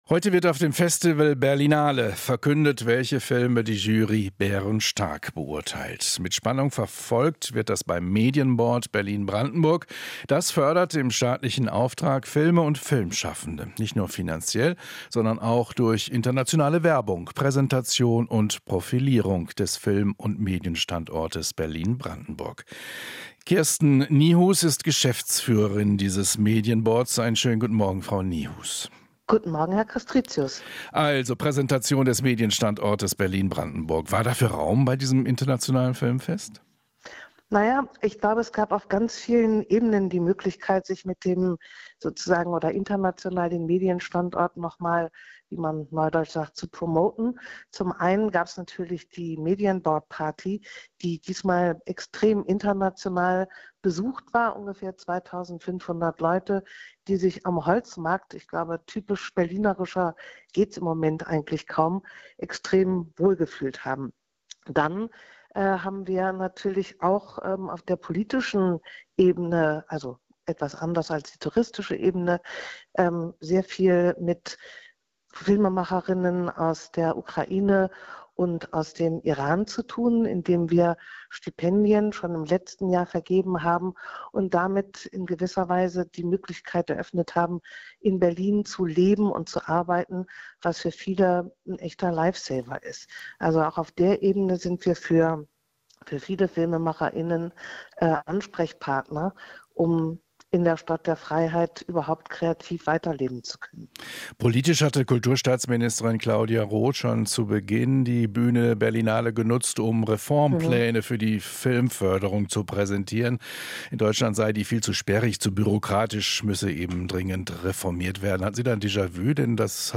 Inforadio Nachrichten, 28.03.2023, 23:00 Uhr - 28.03.2023